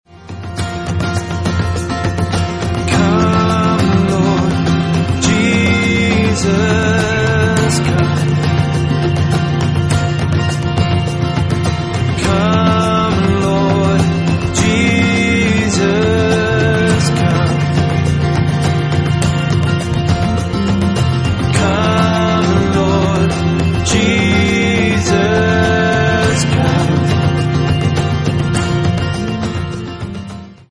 Ein vielfarbiges Studioalbum
• Sachgebiet: Praise & Worship